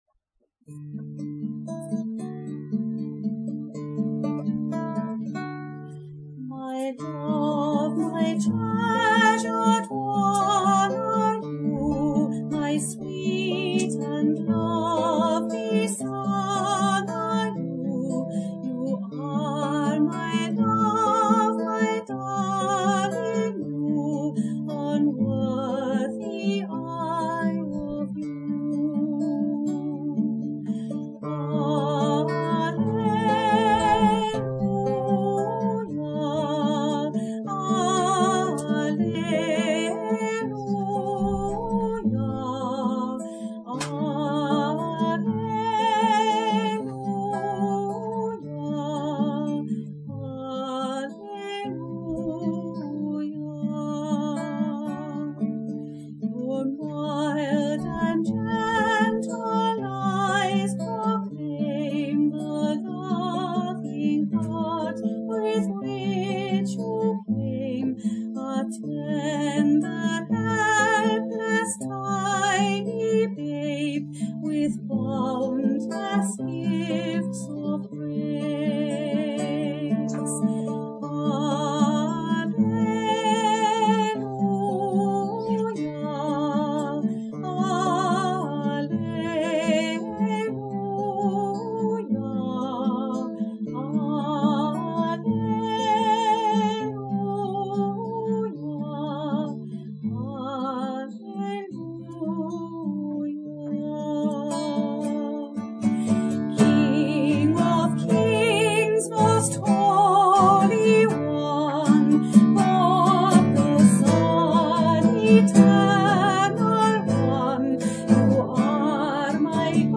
Lullaby
Click to listen to this lovely well-known Gaelic lullaby, which can also be sung as a Christmas carol, as it is below.
guitar